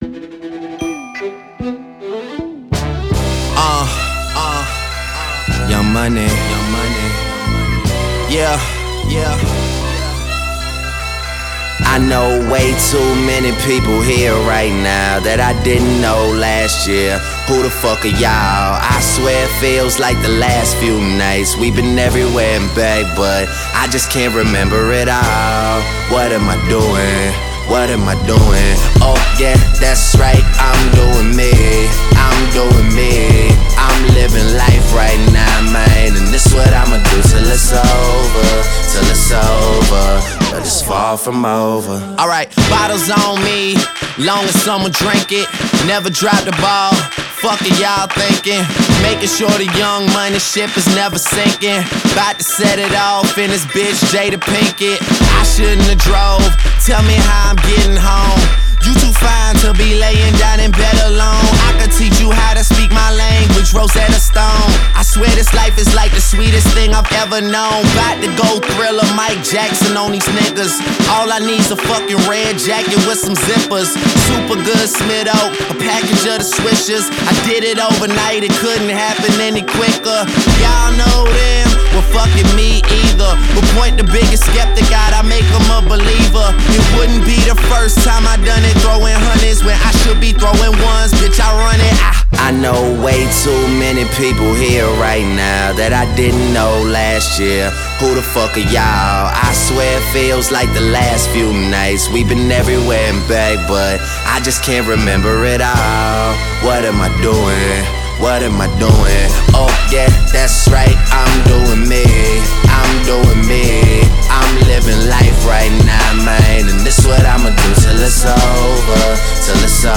Rap/Hip Hop